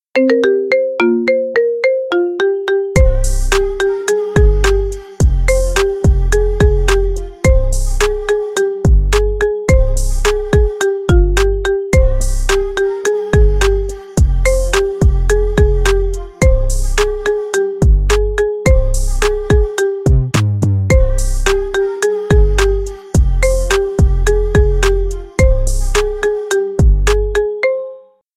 маримба , ремиксы